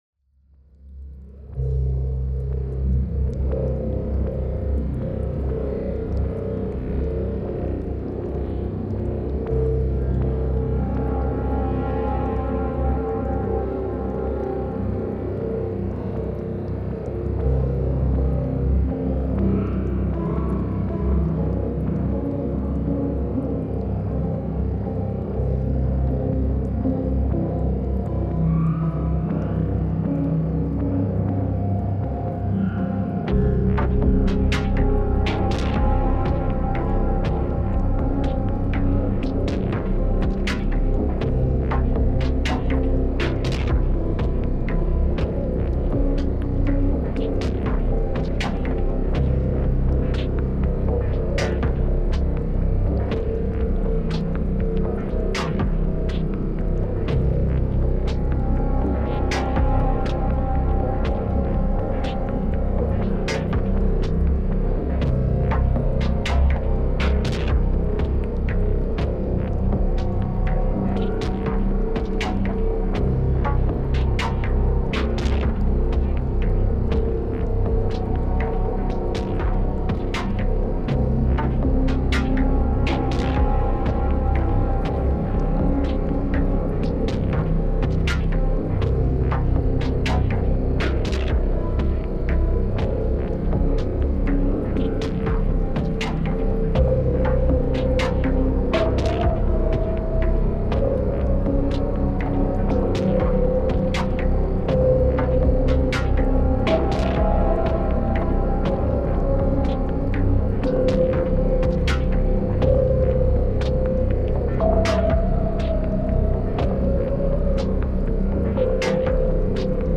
Another creepy ambient track
some Galician insects in a compost mound